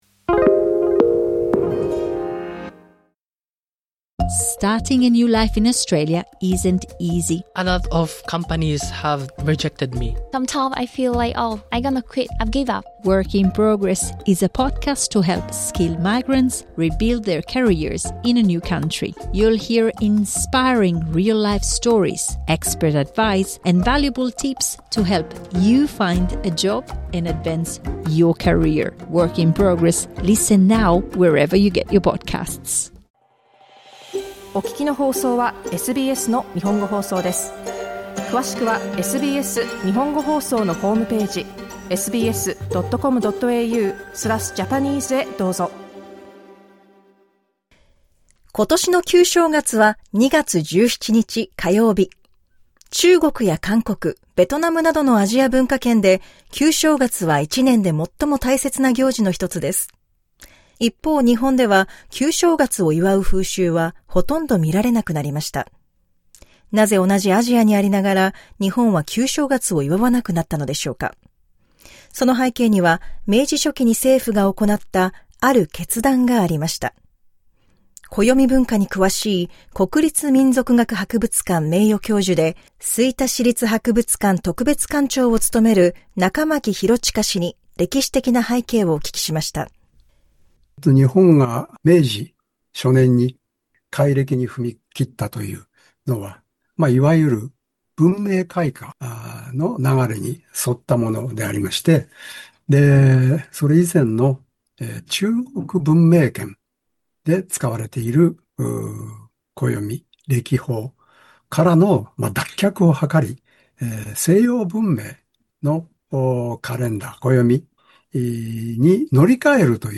暦文化に詳しい専門家の解説に加え、現在も旧正月を祝う風習が残る沖縄や奄美大島ご出身の方から、地域に根付く独自の正月文化についてお話を聞きました。